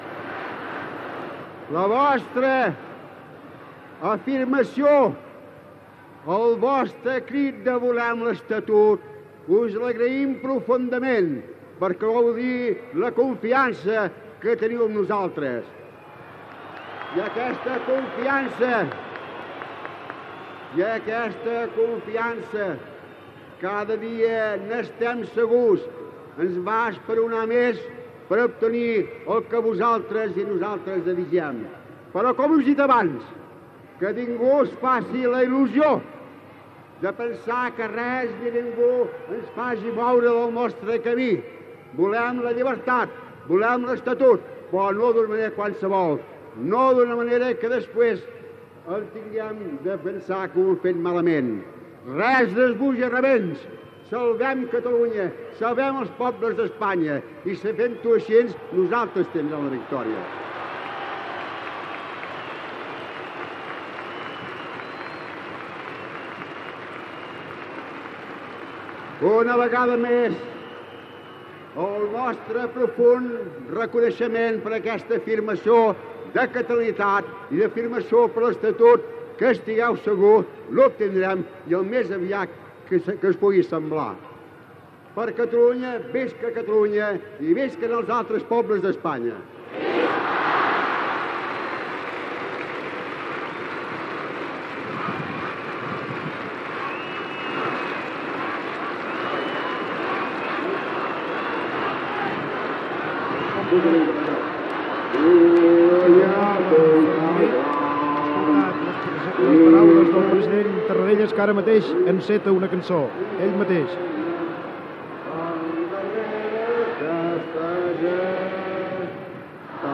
Diada de Sant Jordi. Final del discurs del president de la Generalitat Josep Tarradelles des del balcó del Palau de la Generalitat, demanant l'Estatut de Catalunya, cant dels Segadors a la Plaça Sant Jaume de Barcelona, resum de l'acte
Informatiu